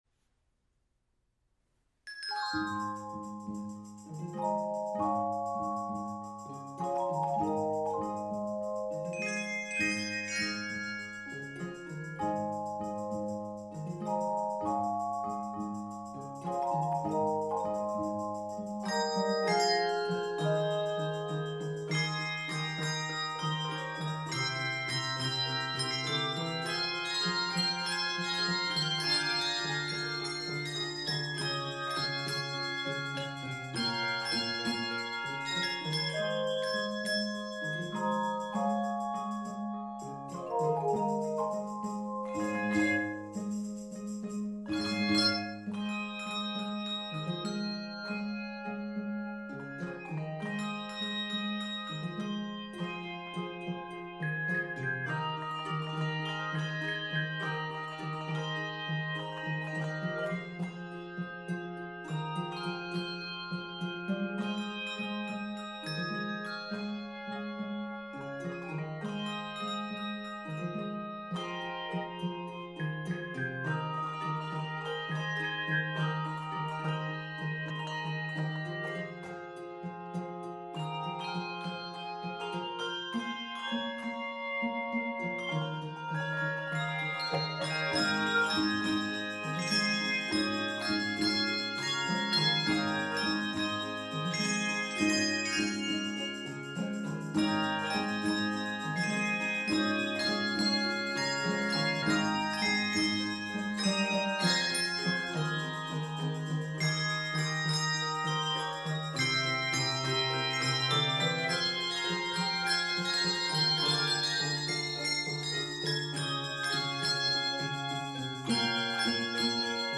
Key of Ab Major. 91 measures.